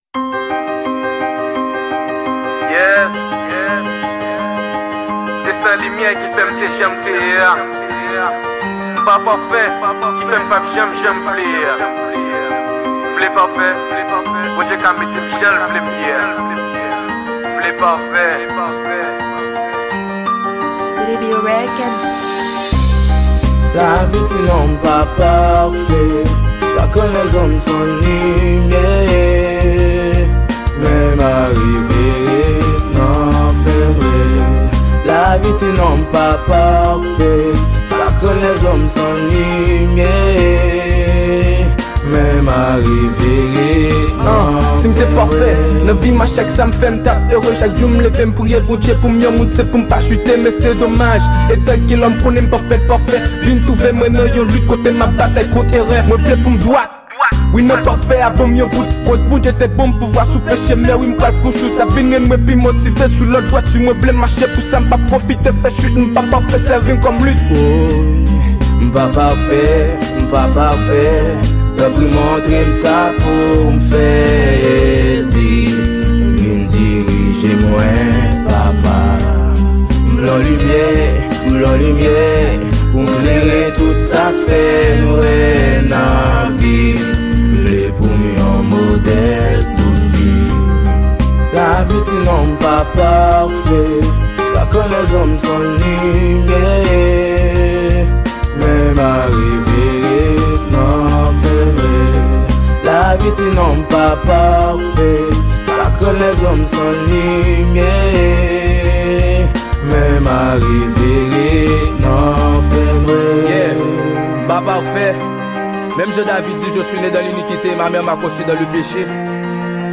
Genre: GOSPEL.